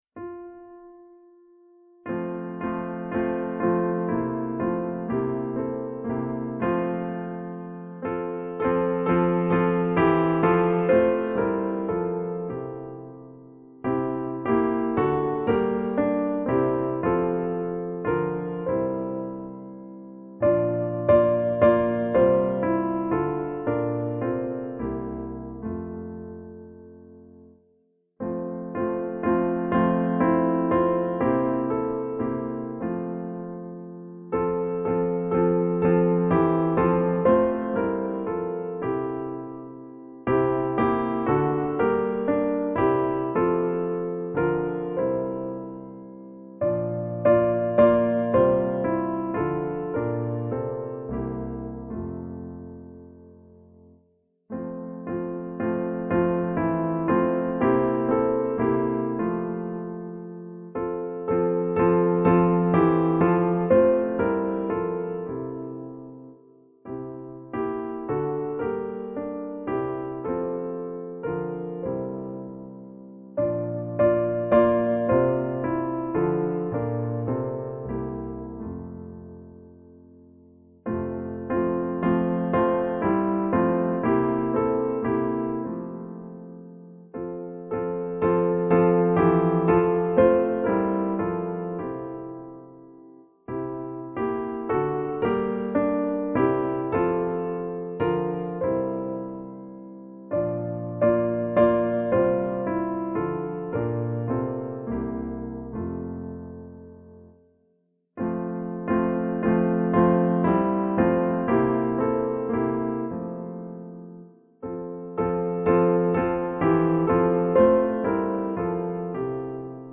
Synges 8 gange